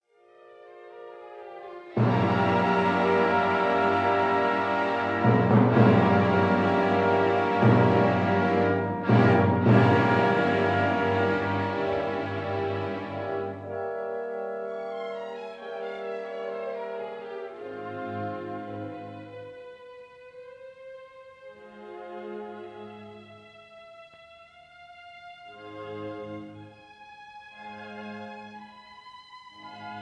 stereo recording made in Hammersmith Town Hall, London